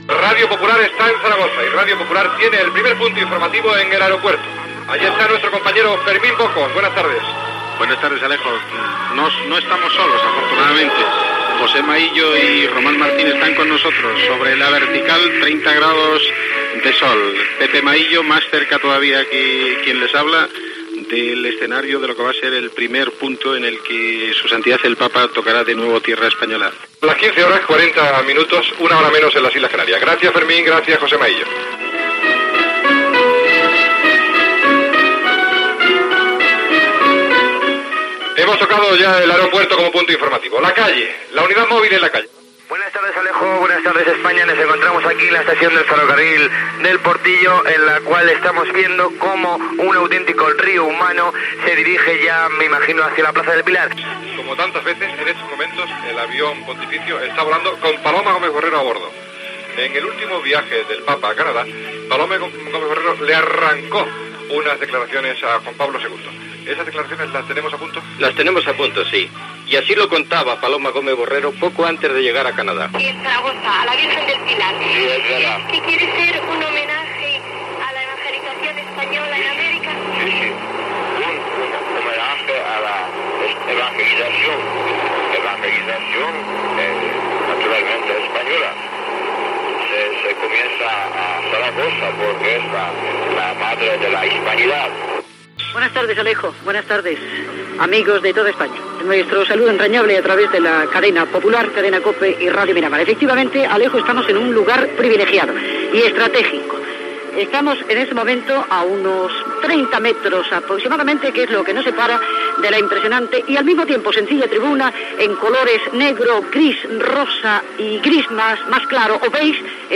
Fragments del programa especial per transmetre la segona visita del Sant Pare Joan Pau II a Espanya, amb connexió amb l'aeroport i l'estació del ferrocarril de Saragossa, paraules de Joan Pau II sobre la verge del Pilar, connexió amb l'avenida de los Pirineos, basílica del Pilar, paruales del Sant Pare Joan Pau II en castellà, arribada de la comitiva a l'avenida Pirineos
Informatiu